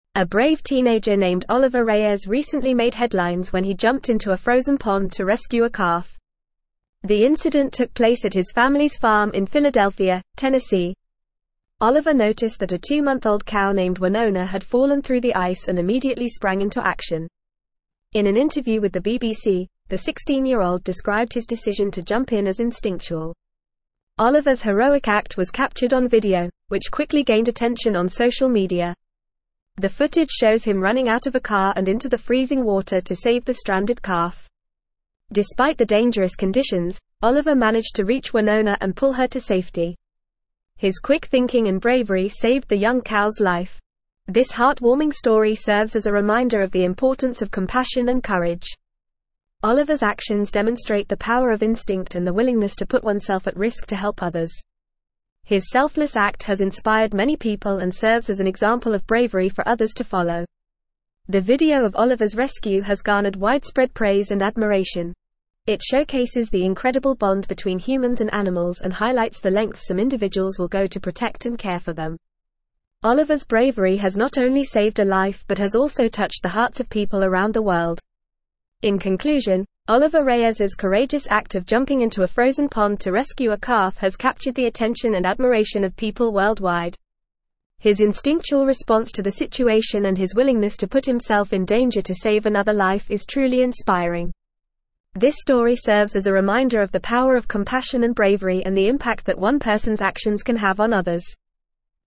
Fast
English-Newsroom-Advanced-FAST-Reading-Teenager-Rescues-Longhorn-Calf-from-Frozen-Pond.mp3